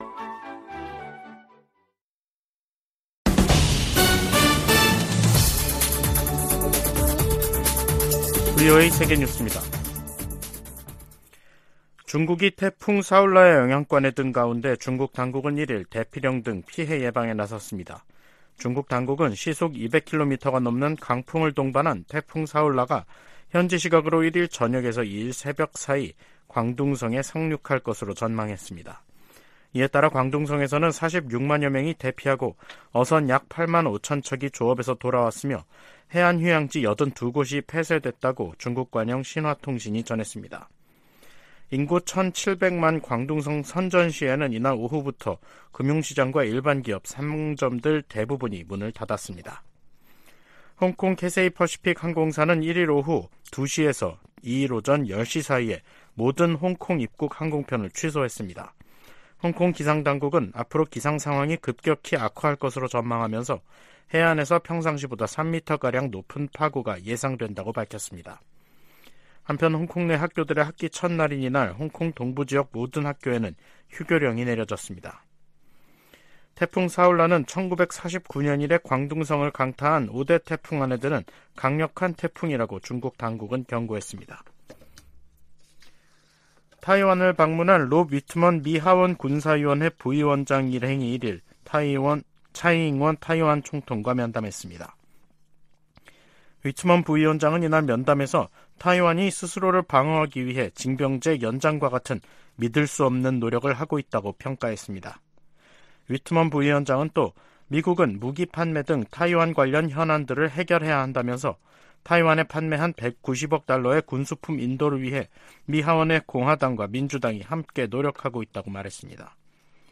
VOA 한국어 간판 뉴스 프로그램 '뉴스 투데이', 2023년 9월 1일 3부 방송입니다. 미국 재무부가 북한의 대량살상무기(WMD)와 탄도미사일 개발에 자금조달을 해온 북한인과 러시아인들을 제재했습니다. 미 의회 산하 중국위원회가 유엔 인권과 난민 기구에 서한을 보내 중국 내 탈북민 북송을 막도록 개입을 요청했습니다. 미 국방부가 북한에 러시아와의 무기 거래 협상 중단을 촉구했습니다.